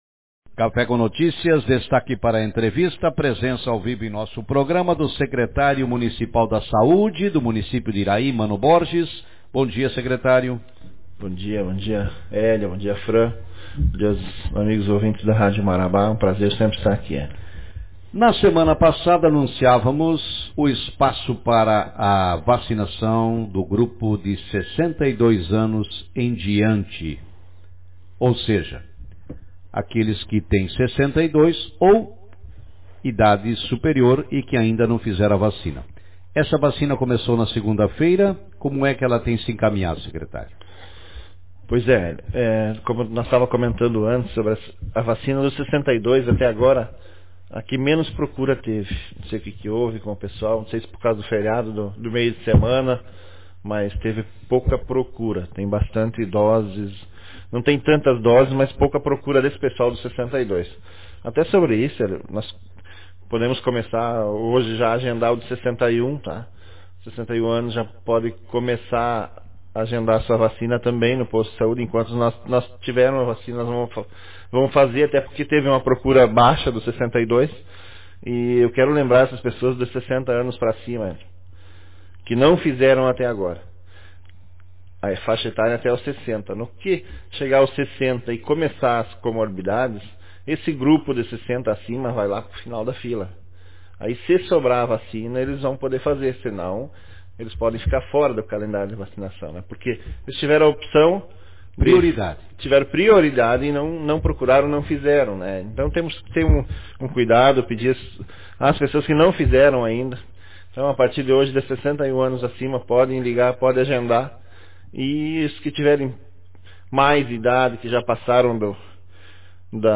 Secretário Mano Borges convoca idosos a partir de 61 anos para a vacinação contra o coronavírus Autor: Rádio Marabá 22/04/2021 0 Comentários Manchete Na manhã de hoje, no espaço do programa Café com Notícias, o secretário de Saúde, Mano Borges, falou sobre a sequência da vacinação contra o coronavírus da população iraiense. Lembrou que muitas pessoas da faixa etária de 62 anos não compareceram nesta semana para vacinação. Ao mesmo tempo, já antecipou que a partir desta quinta-feira, estão sendo agendadas vacinas para 61 anos em diante.